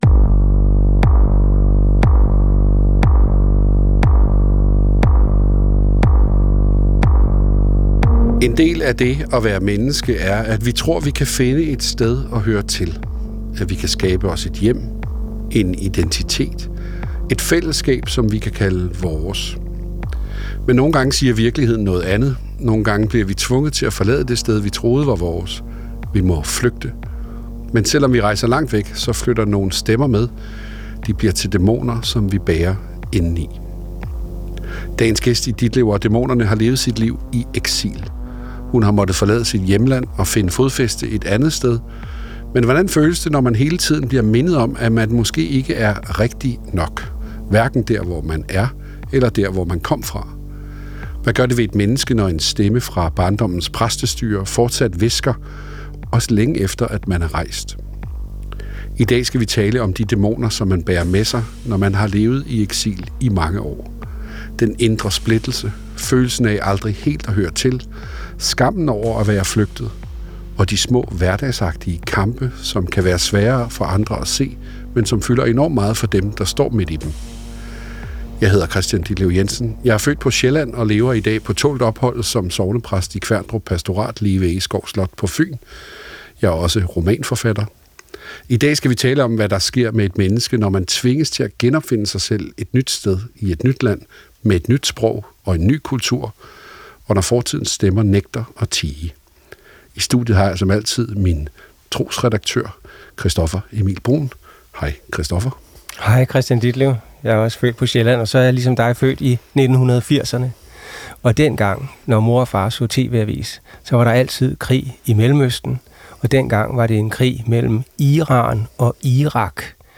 Vært: Kristian Ditlev Jensen.